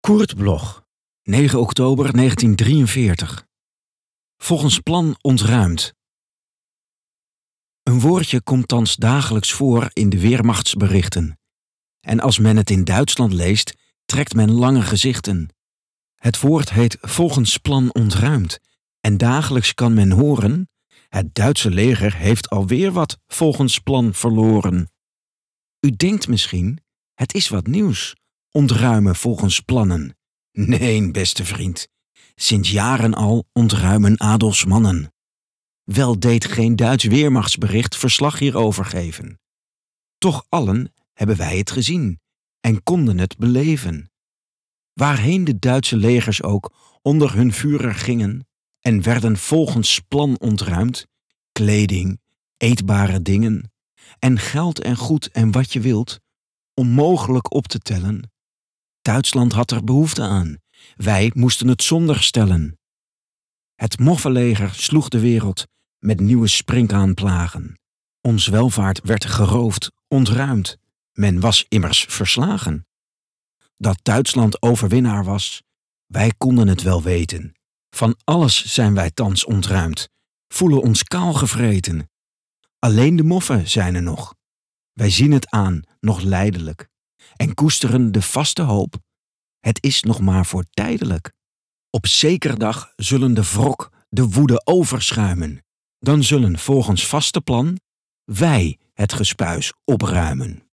Aufnahme: MOST, Amsterdam · Bearbeitung: Kristen & Schmidt, Wiesbaden